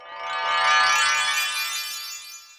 sfx_magic.ogg